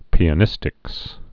(pēə-nĭstĭks)